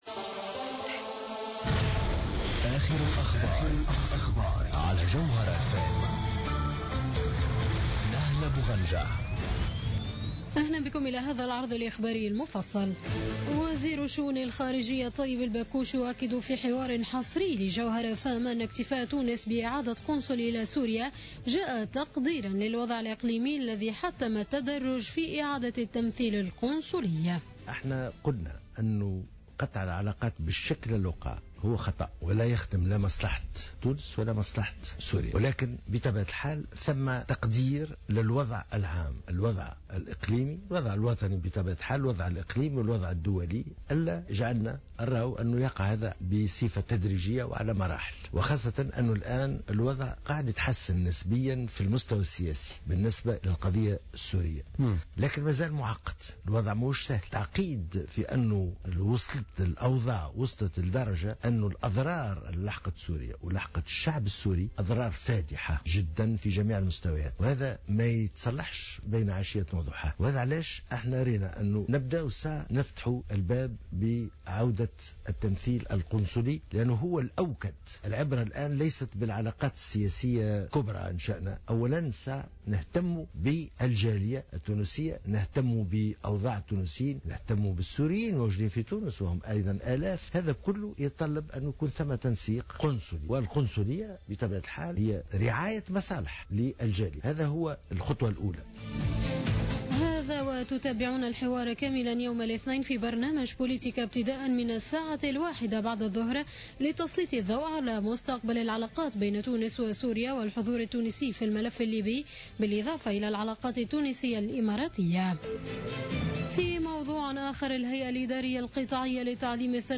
نشرة أخبار منتصف الليل ليوم الأحد 6 سبتمبر 2015